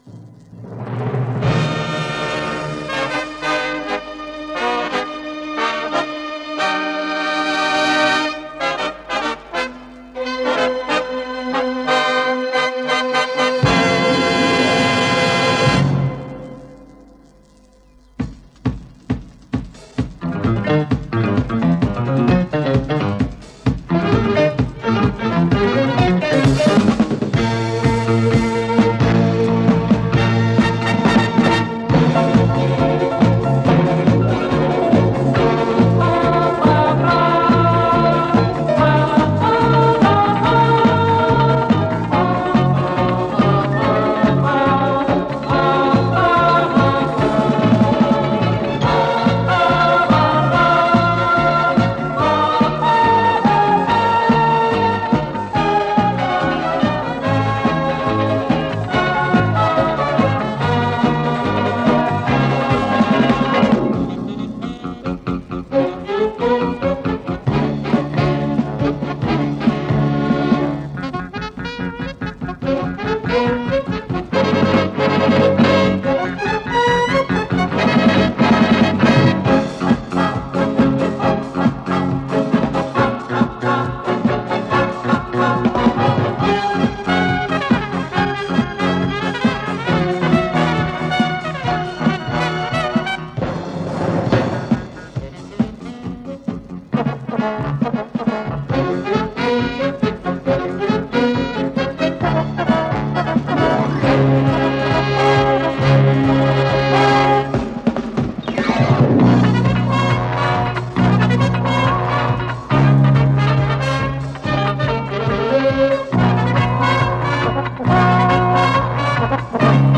Записывал с телевизора из передачи, посвящённой оркестру.